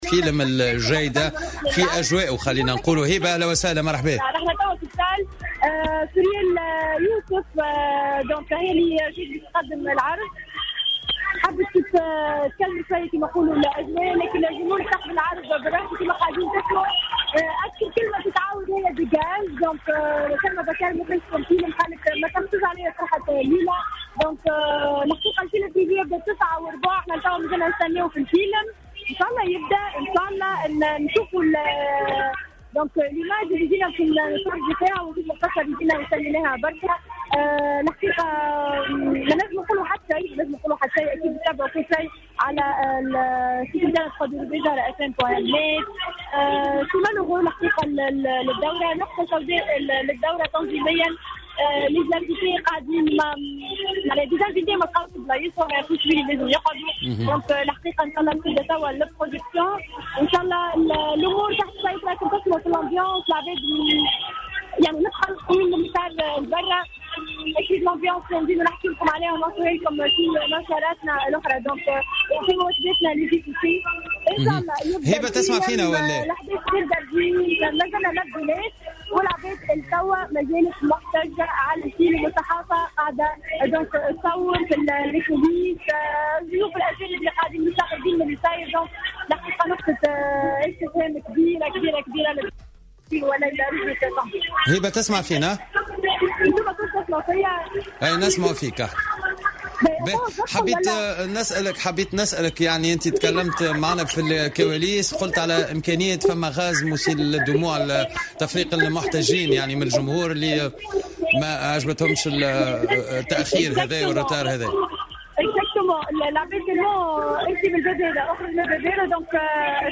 شهد محيط قاعة الكوليزي بالعاصمة، عشية اليوم الخميس، حالة من الفوضى التي سبقت عرض فيلم "الجايدة" في إطار مهرجان قرطاج السينمائي 2017.